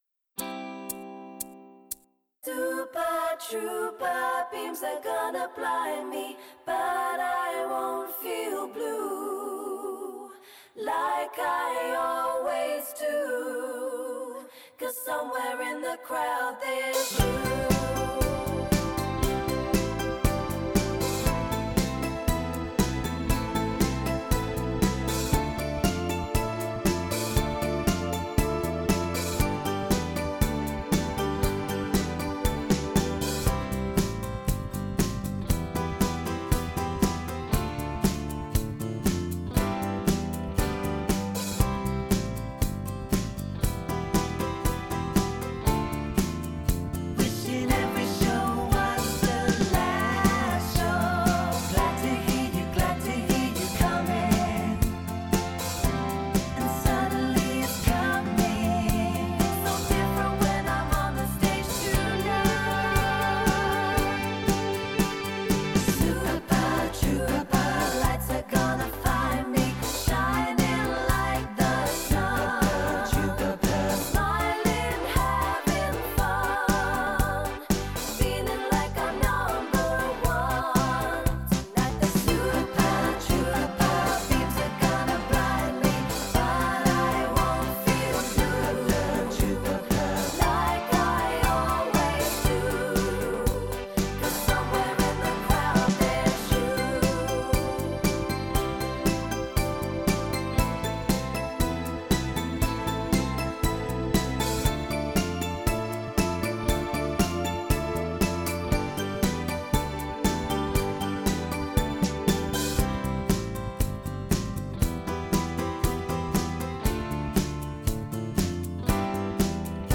4 VOCALS